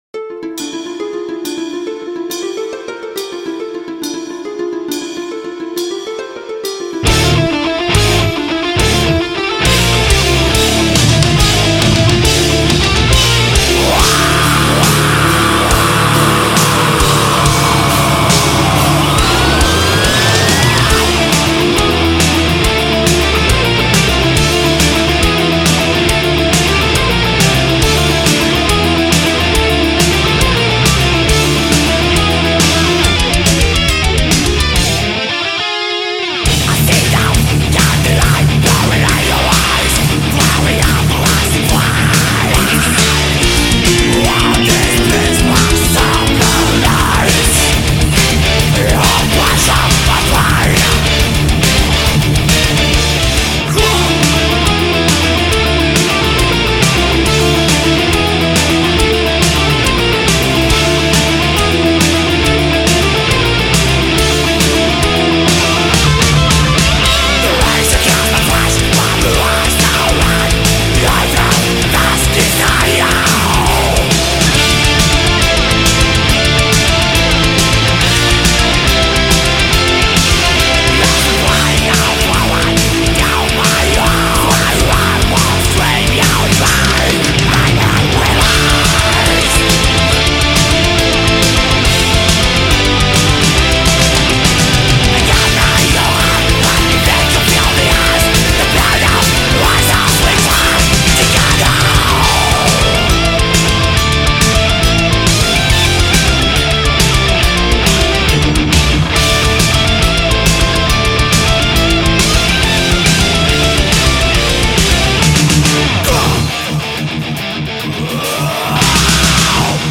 Power Metal
>growl